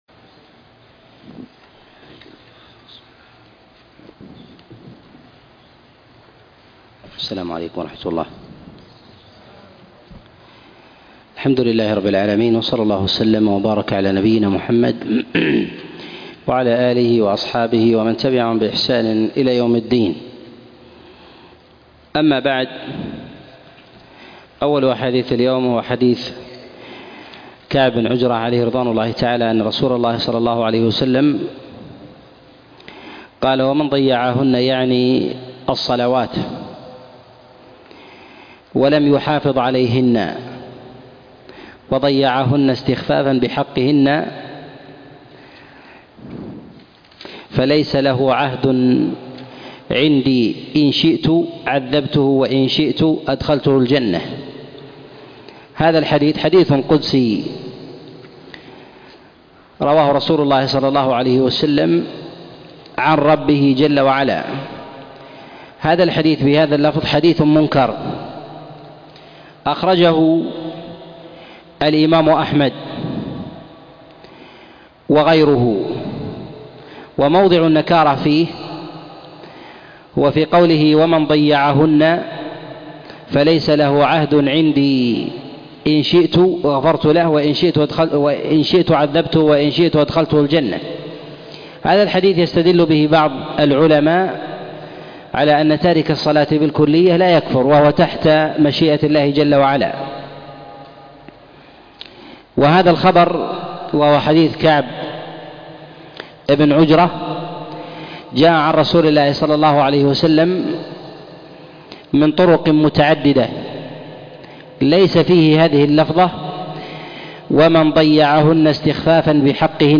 الأحاديث المعلة في الصلاة الدرس 1